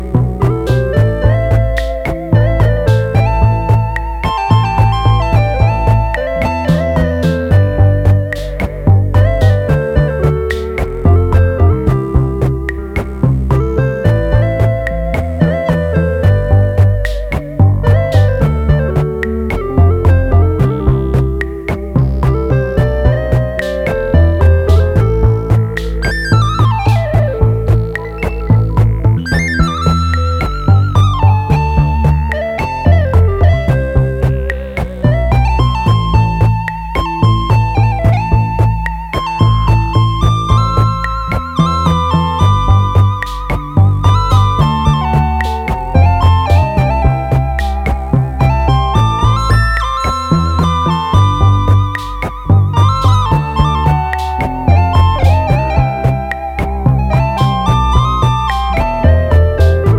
バレアリックやコズミック系DJも要チェックな個性的なサウンドがギッシリ！